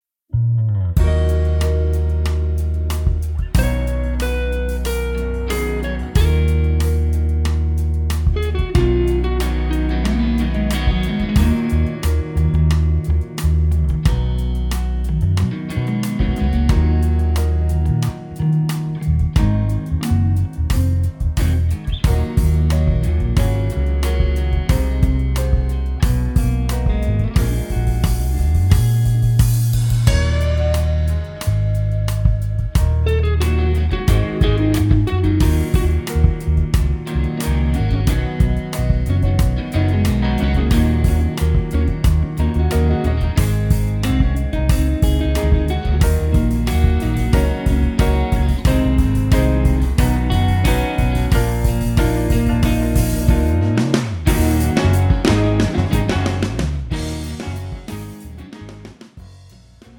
음정 (-1키)
장르 pop 구분 Pro MR